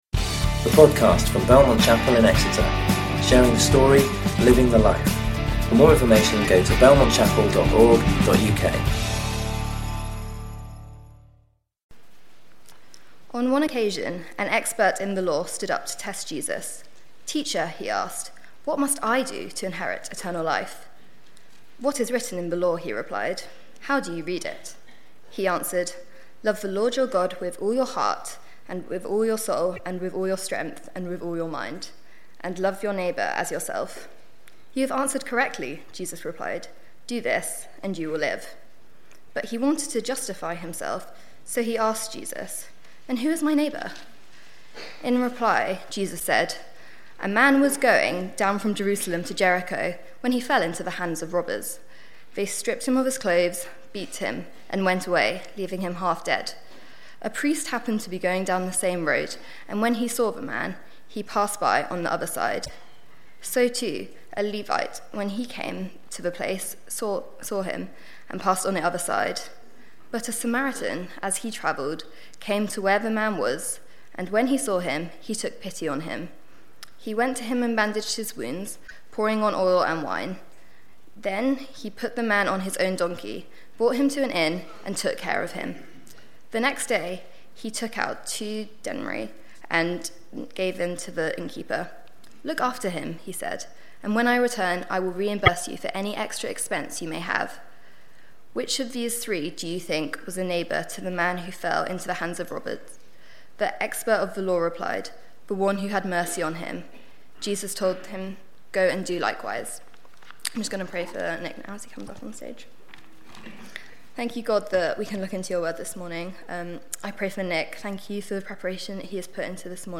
You can listen to or download sermons from Belmont Chapel.